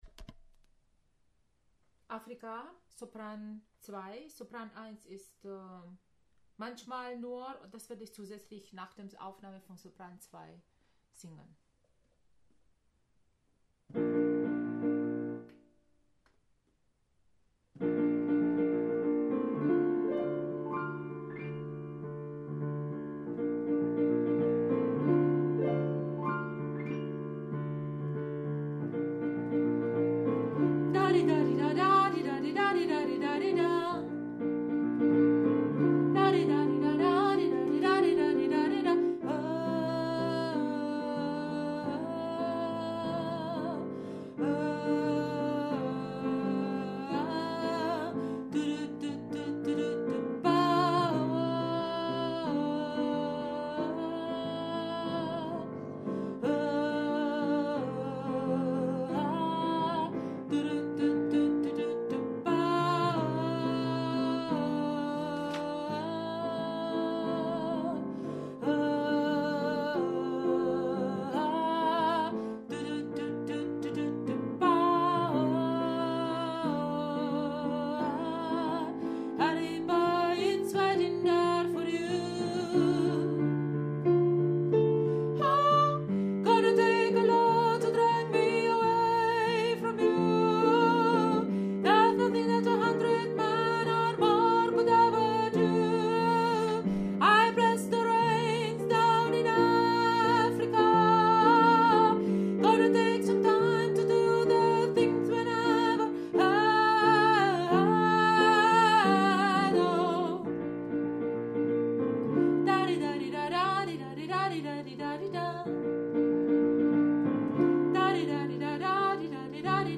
Africa – Sopran